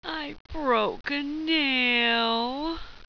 Index of /tactics/sfx/pain/sultry
i_broke_a_nail.wav